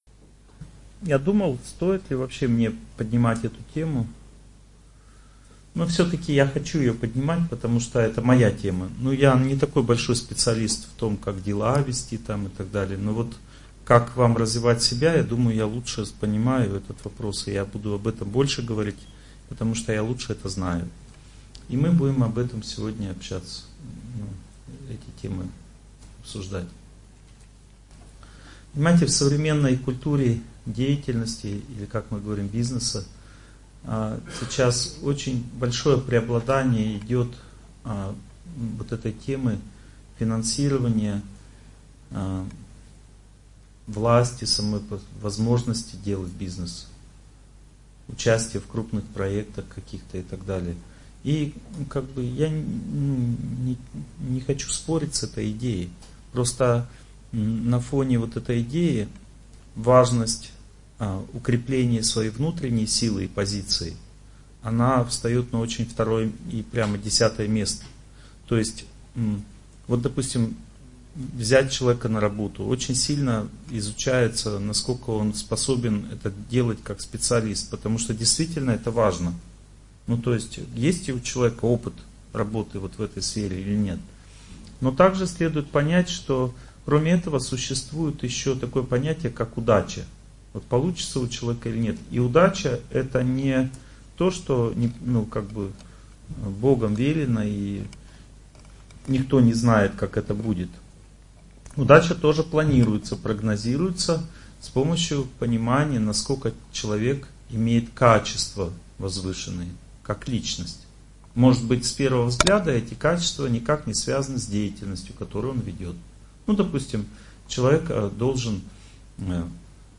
Аудиокнига Как улучшить свою судьбу в бизнесе | Библиотека аудиокниг